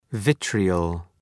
{‘vıtrıəl}
vitriol.mp3